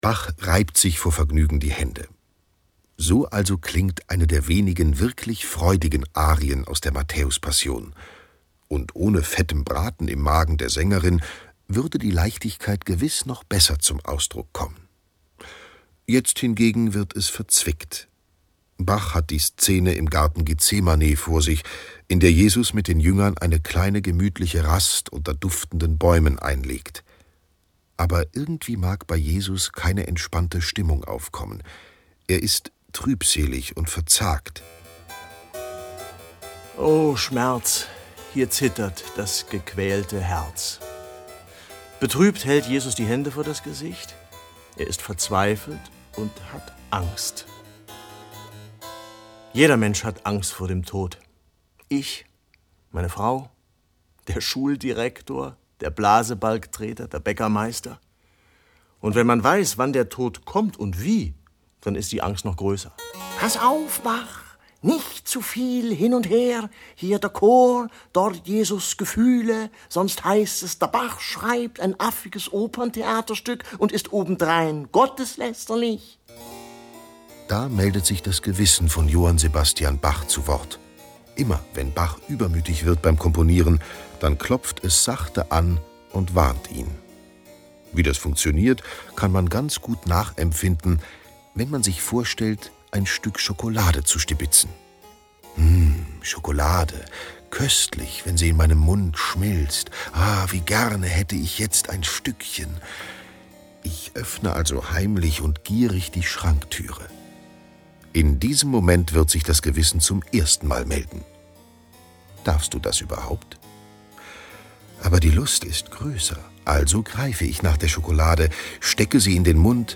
Hörspiel mit Musik-CD